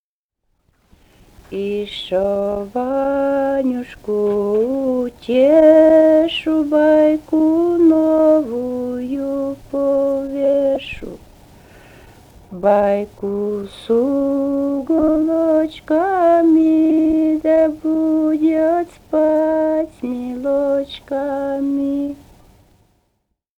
Живые голоса прошлого 165. «Ишшо Ванюшку утешу» (колыбельная).